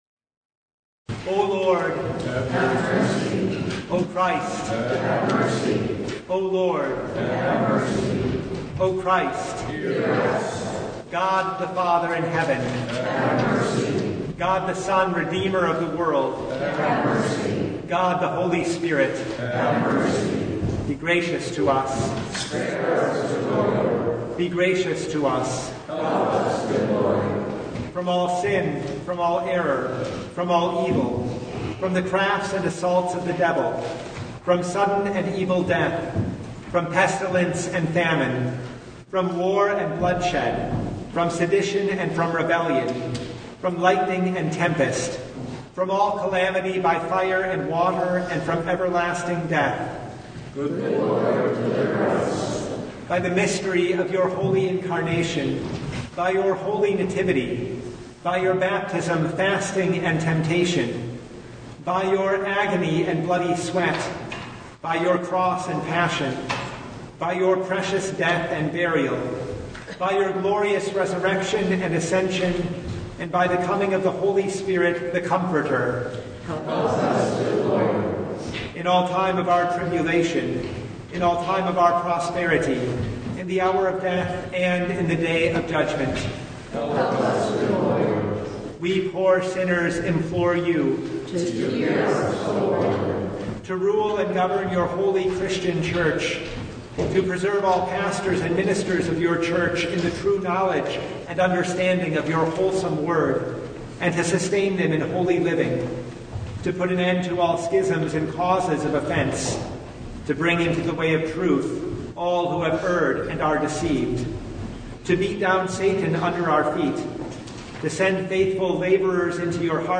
Passage: John 3:25-36 Service Type: Lent Midweek Noon
Full Service